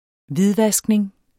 Udtale [ ˈviðˌvasgneŋ ]